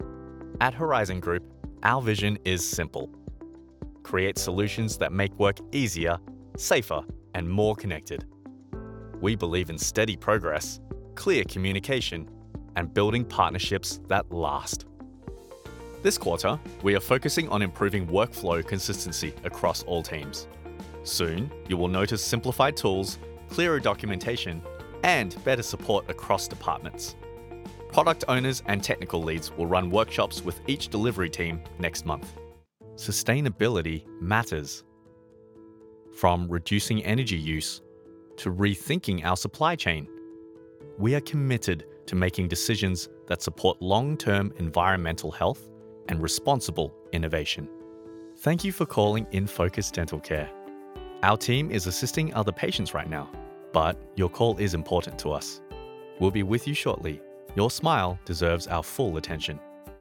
E=learning, Corporate & Industrial Voice Overs
Adult (30-50) | Older Sound (50+)